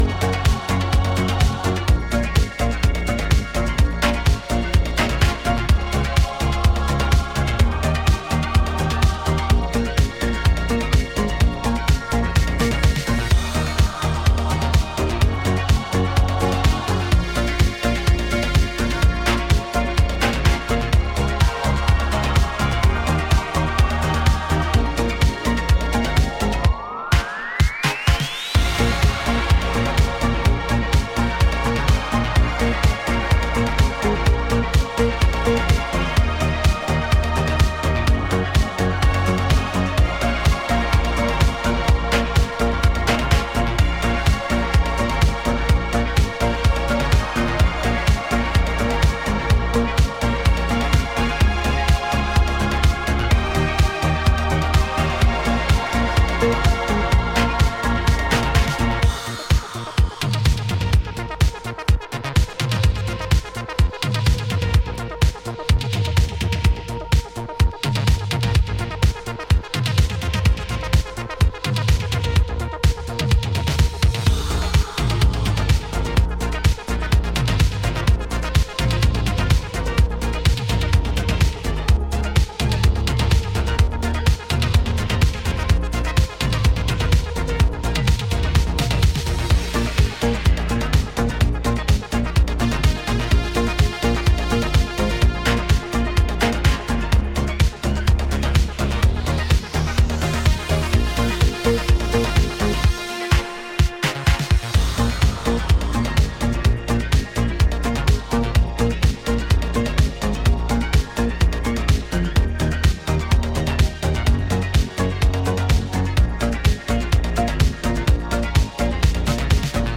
アナログシンセの軽快なメロディーや歯切れの良いパーカッション